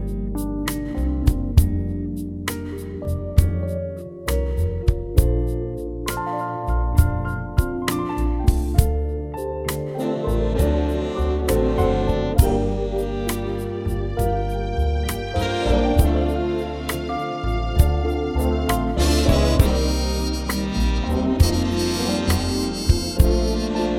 One Semitone Down Jazz / Swing 4:24 Buy £1.50